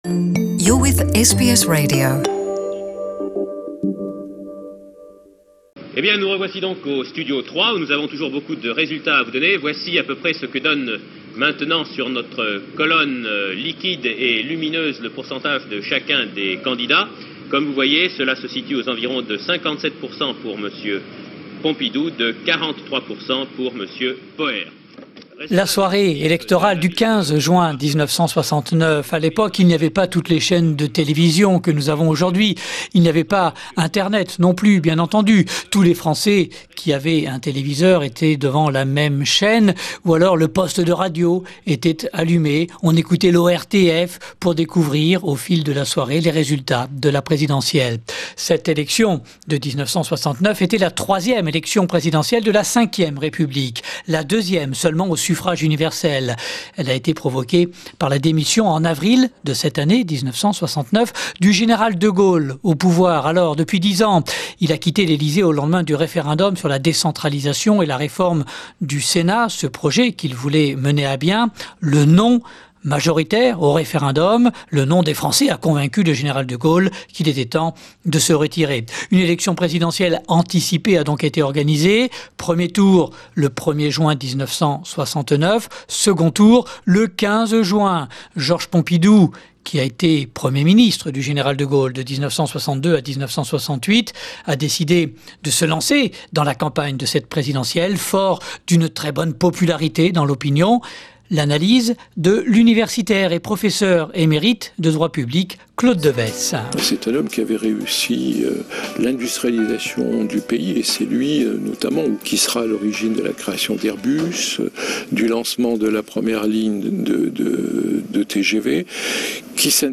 Le 15 juin 1969, Georges Pompidou était élu Président de la République française. Retour sur cet épisode avec les archives sonores de l’Institut National de l’Audiovisuel.